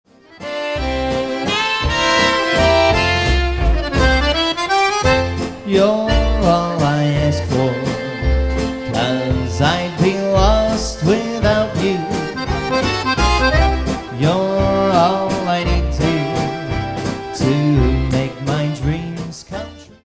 A Milwaukee, Wisconsin Polka Band